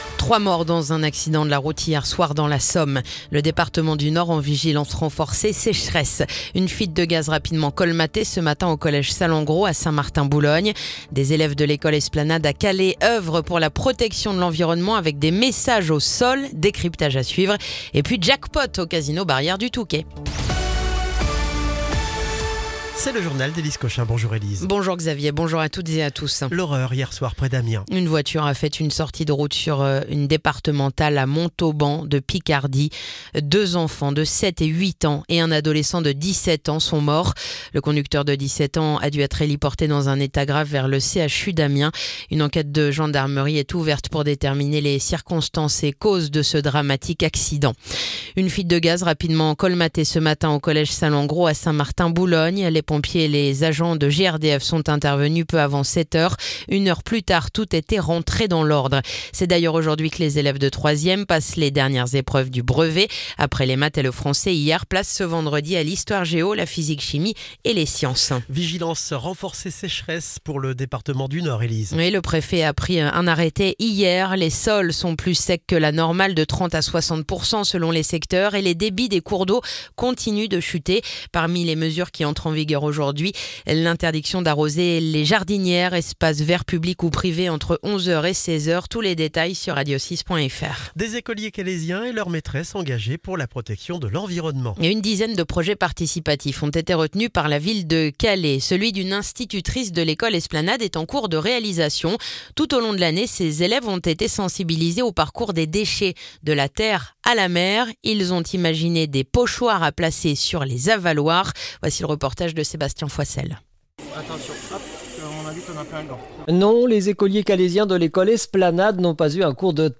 Le journal du vendredi 27 juin